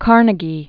(kärnə-gē, kär-nāgē, -nĕgē), Andrew 1835-1919.